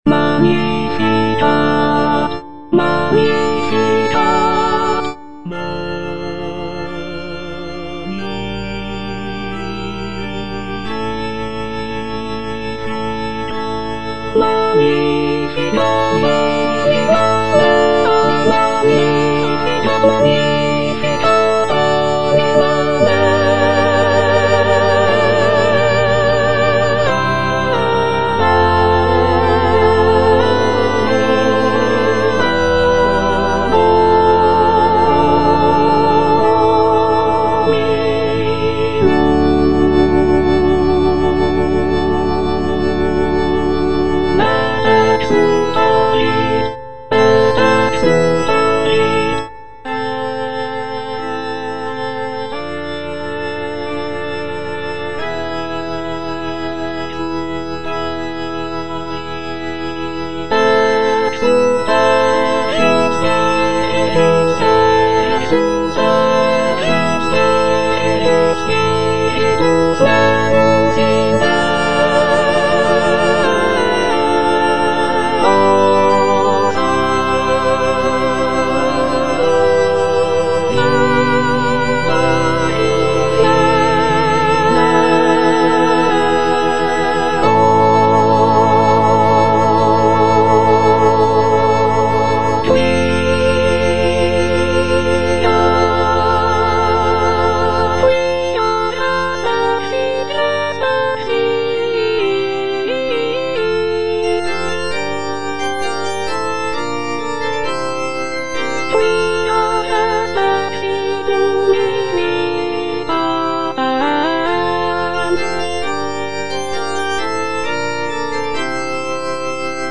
Soprano I (Emphasised voice and other voices) Ads stop
sacred choral work